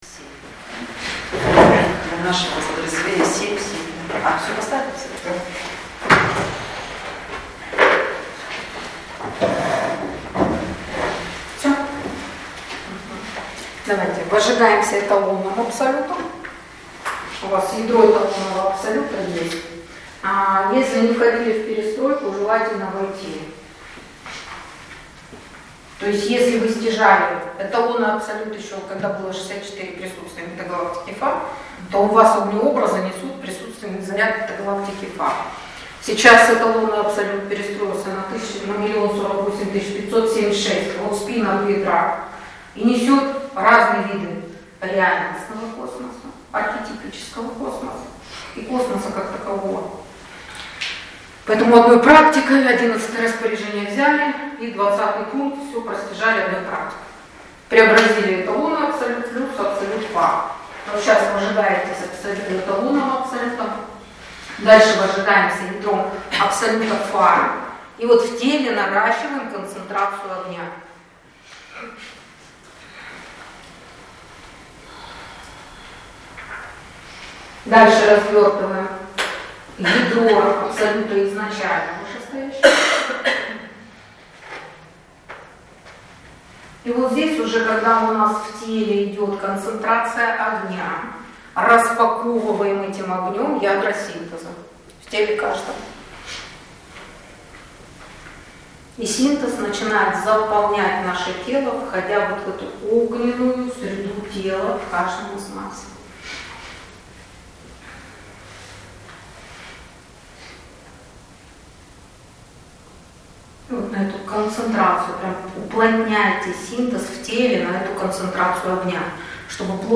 Занятия для всех Компетентных | Место: Зеленогорск